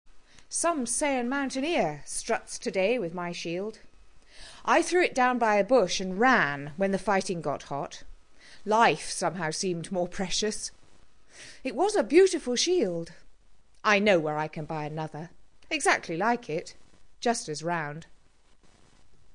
spoken version